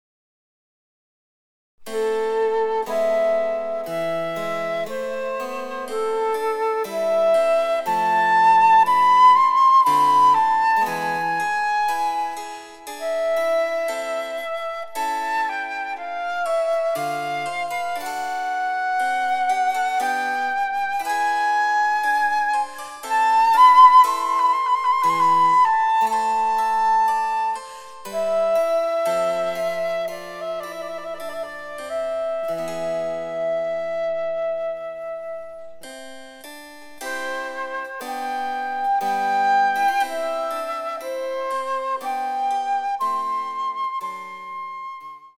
第３楽章は再びアダージョ。
おしまい近くに出てくる「反復進行」のテンションコードがかもし出す愁いに満ちた雰囲気は魅惑的です。
■フルートによる演奏
チェンバロ（電子楽器）